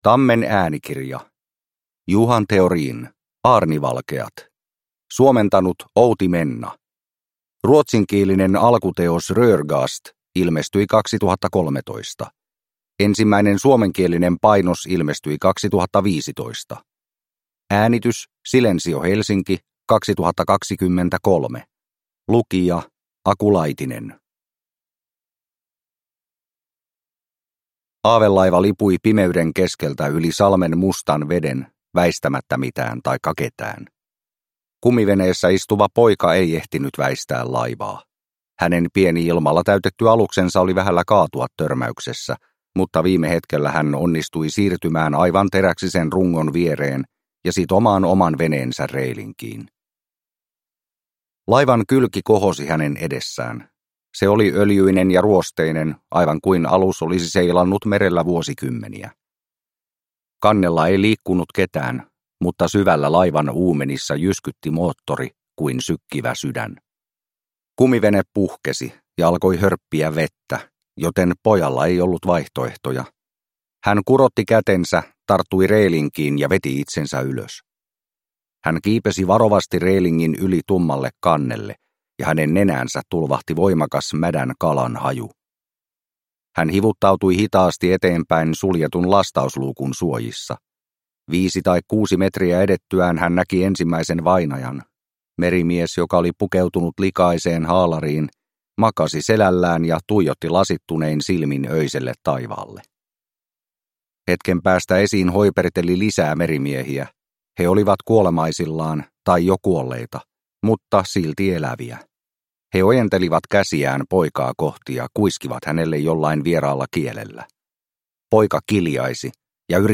Aarnivalkeat – Ljudbok – Laddas ner